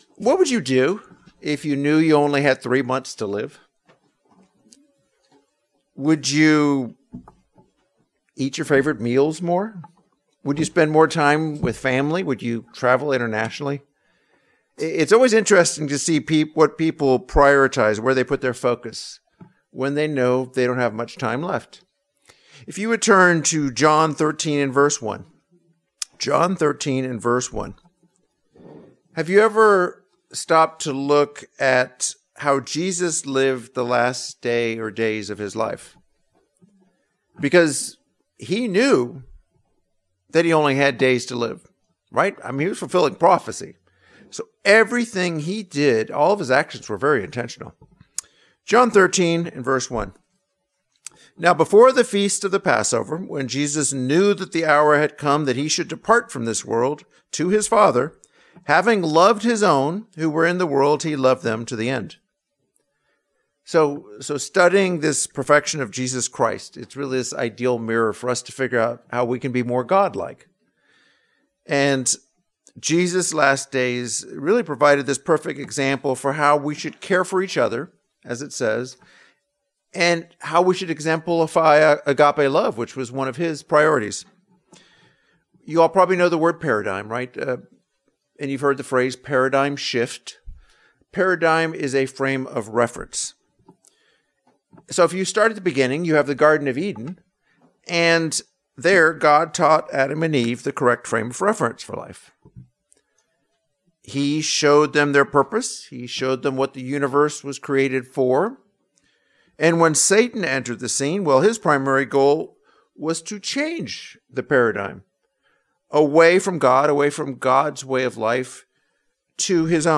What he taught was a lesson on Godly love and how we should care for each other and exemplify that love. This sermon covers six lessons on Godly love as well as how we can best love God, our neighbor, and ourselves.